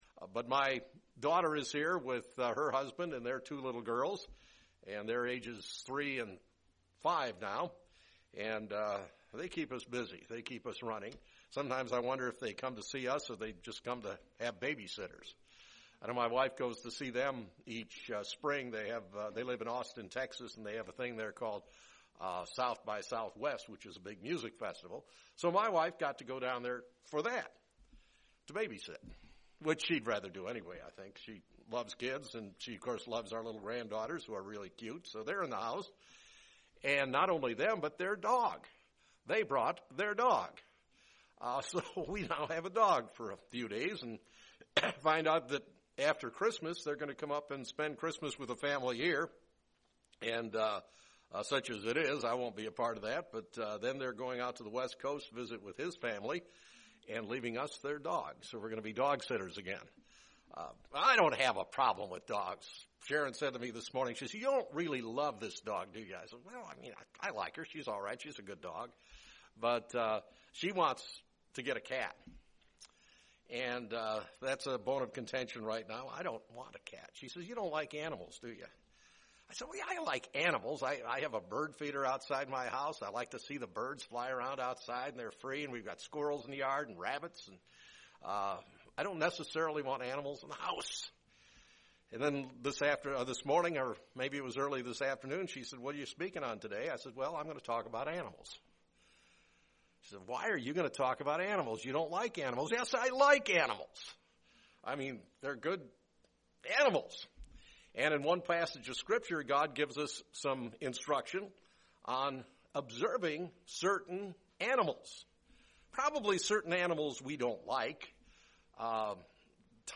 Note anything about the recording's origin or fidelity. Given in Springfield, MO